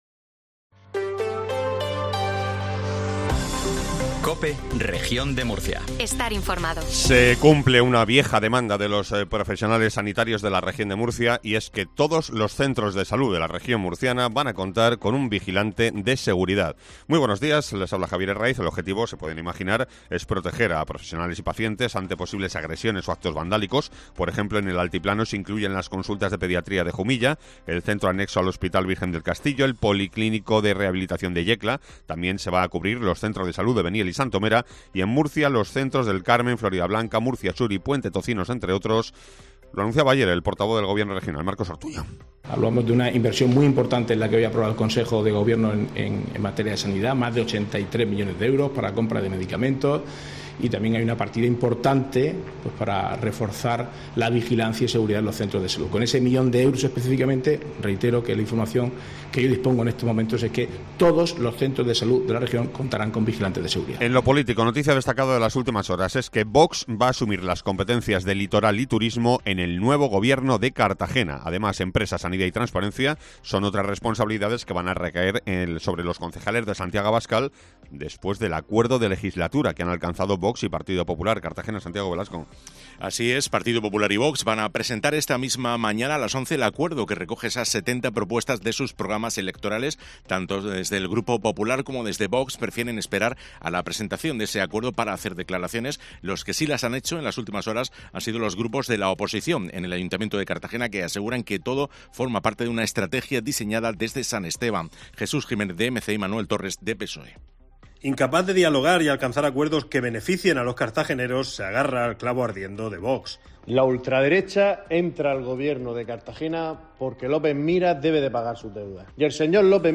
INFORMATIVO MATINAL COPE REGION DE MURCIA